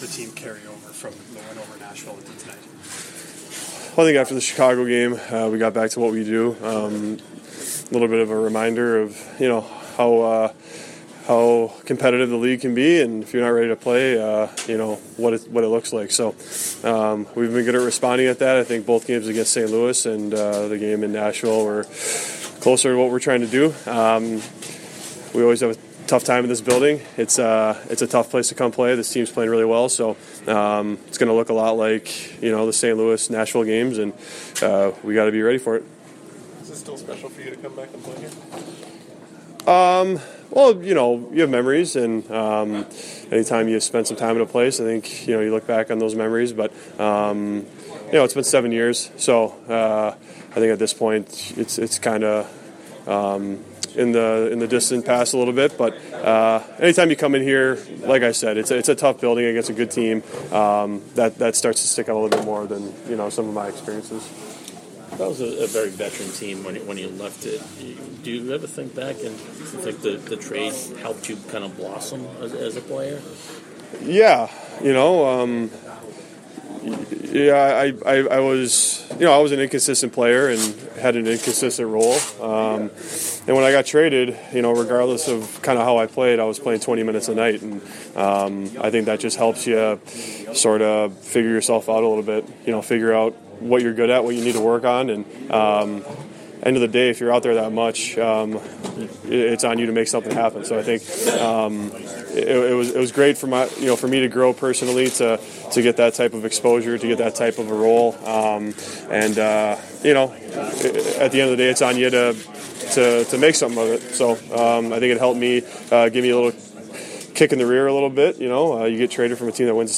Pre-game audio:
Audio courtesy of TSN 1290 Winnipeg.